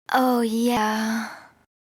Female Funny Oh Yeah Sound Effect Free Download
Female Funny Oh Yeah